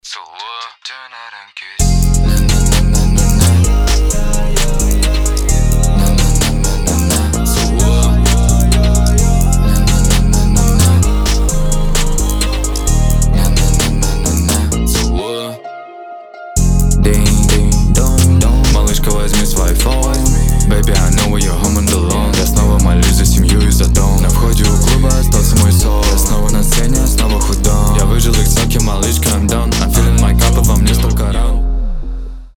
рэп
ремиксы
mashup